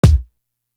Legacy Kick.wav